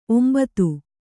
♪ ombatu